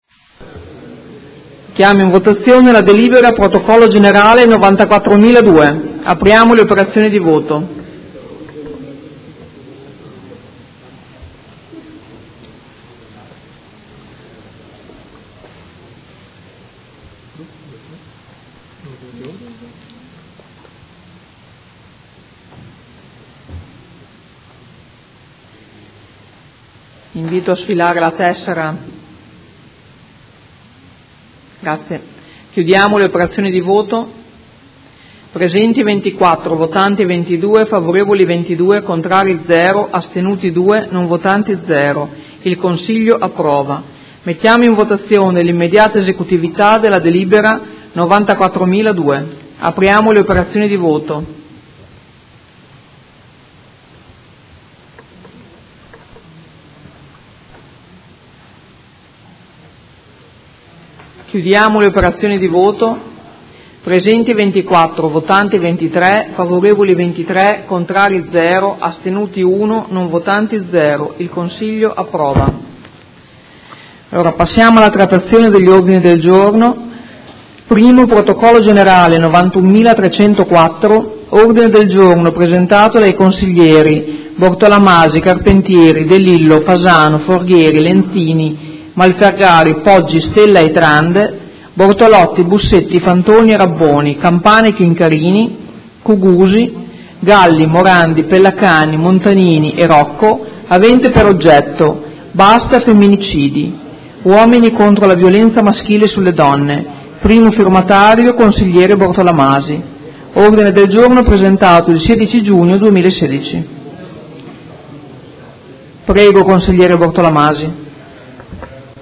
Presidente
Seduta del 7 luglio.